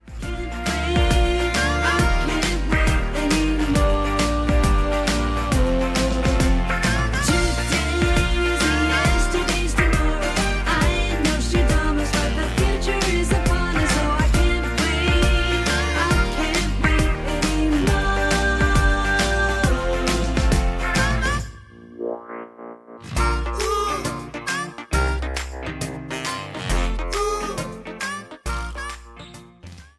Bb
Backing track Karaoke
Pop, 2010s